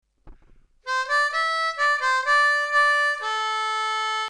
Lee Oskar Melody Maker in G
Opening riff..